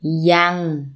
– yang